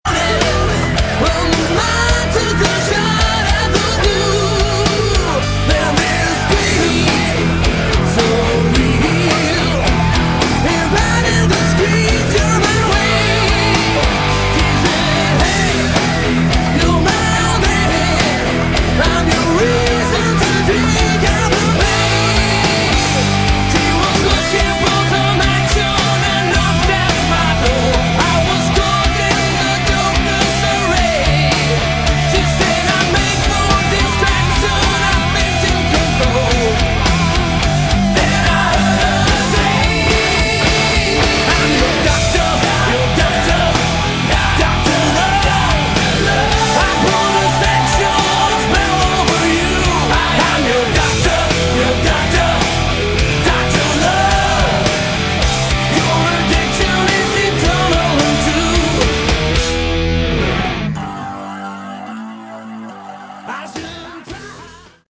Category: Hard Rock
lead vocals
keyboards
bass
lead guitar
drums